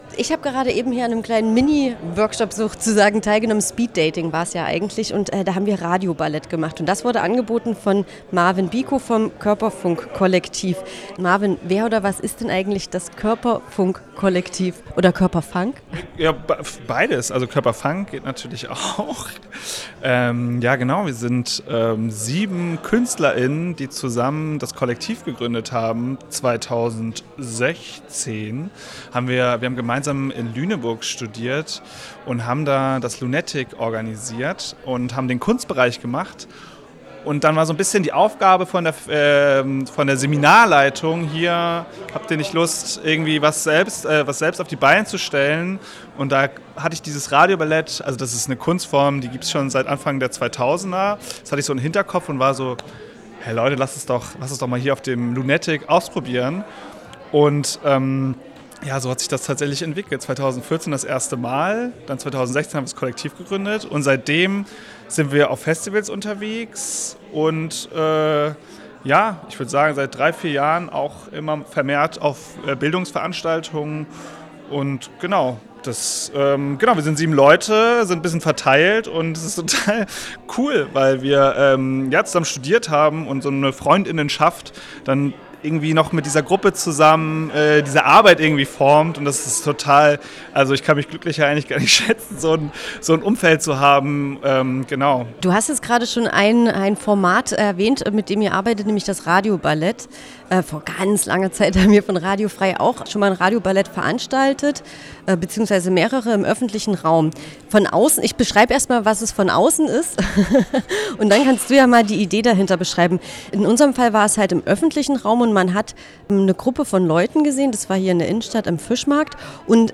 Fachtag "Demokratie erlebbar machen" | Interviews zum Nachhören